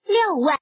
Index of /client/common_mahjong_tianjin/mahjonghntj/update/1307/res/sfx/woman/